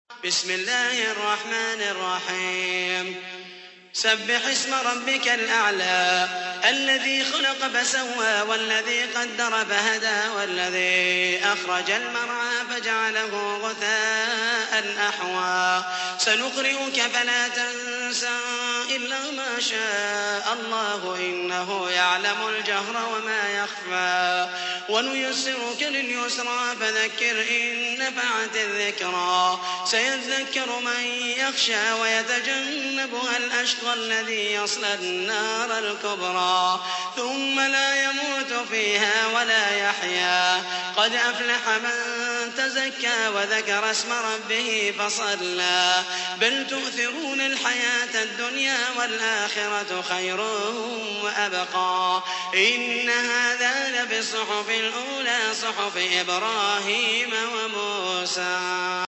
تحميل : 87. سورة الأعلى / القارئ محمد المحيسني / القرآن الكريم / موقع يا حسين